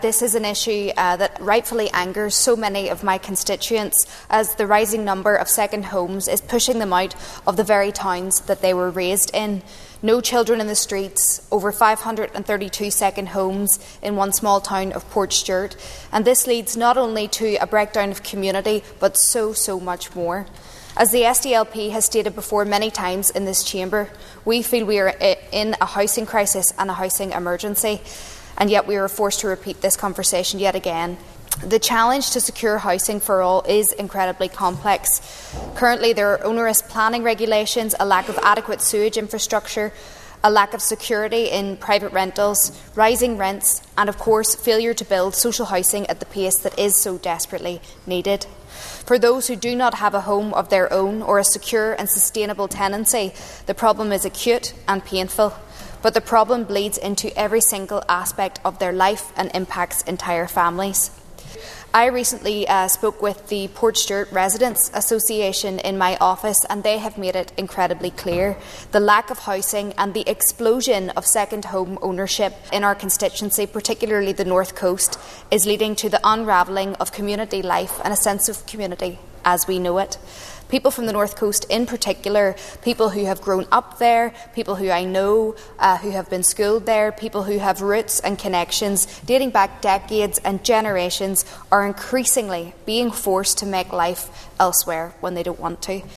Ms Hunter told the Assembly the situation in towns like Portstewart is now critical………….